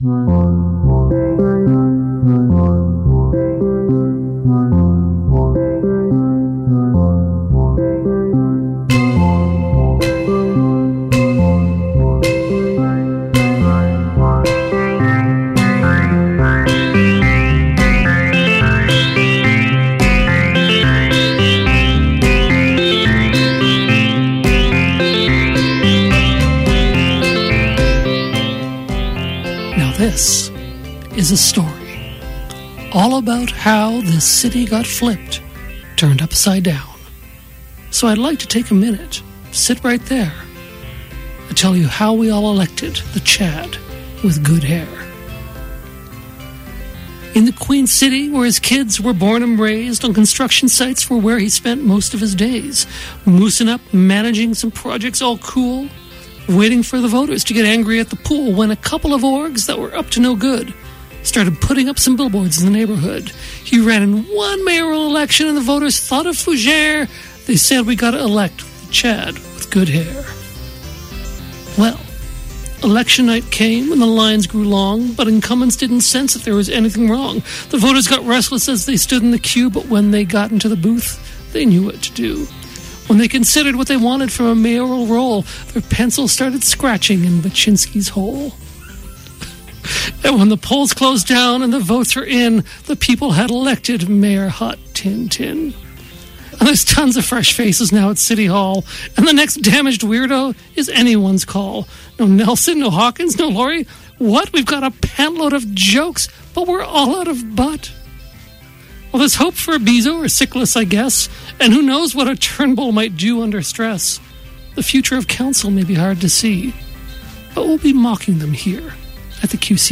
Originally broadcast on 91.3FM CJTR.